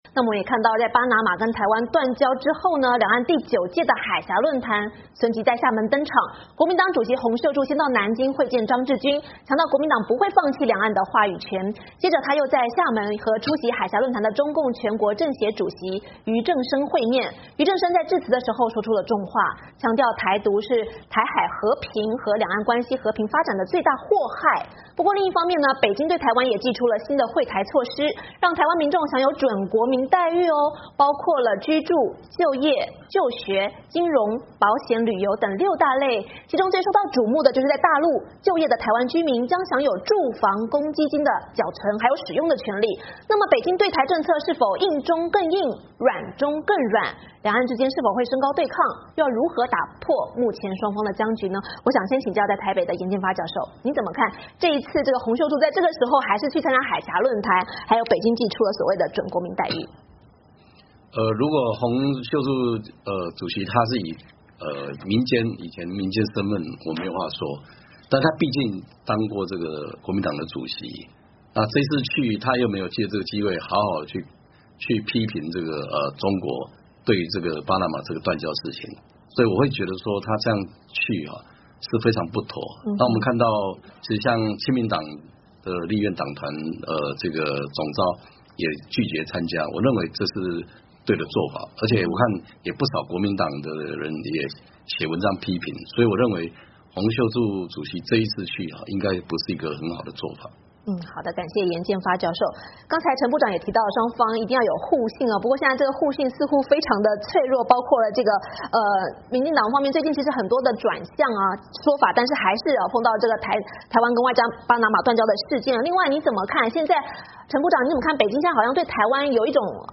会巴拿马与台湾断交之后，两岸第九届海峡论坛随即在厦门登场，国民党主席洪秀柱先到南京会见张志军，强调国民党不会放弃两岸的话语权，接着又在厦门与出席海峡论坛的中共全国政协主席俞正声面，俞正声在致词时说出重话，强调台独是台海和平与两岸关系和平发展的最大祸害，不过另一方面，北京对台湾祭出新的惠台措施，让台湾民众享有“准国民待遇”，包括居住、就业、就学、金融、保险、旅游等六大类，其中最受到瞩目的就是在大陆就业的台湾居民将享有住房公积金缴存和使用权利，北京对台政策是否硬中更硬、软中更软? 两岸之间是否会升高对抗? 双方又要如何打破目前的僵局?海峡论谈今晚邀请台湾前外交部长程建人以及台湾前外交部研设会主委颜建发教授为您深入分析。